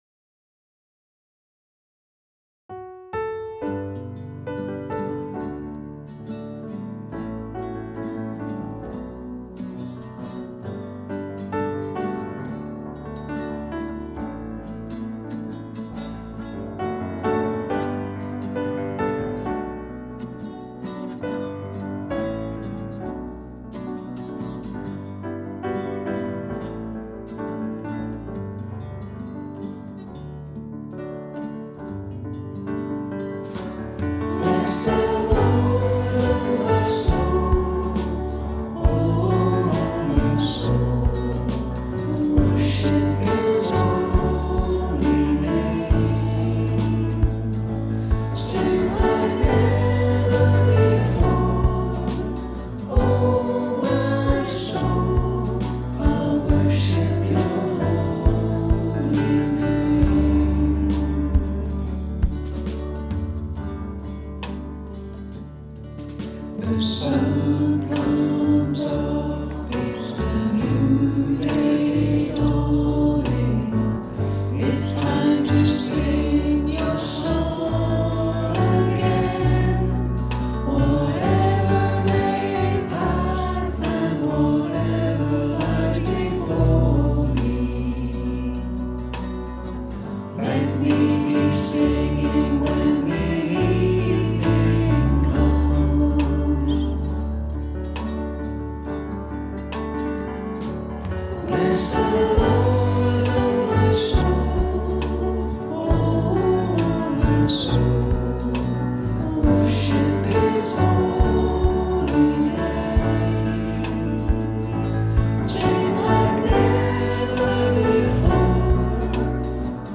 Praise Band At Home
Whilst we are unable to be together to play, the praise band and friends have been recording for the message each week.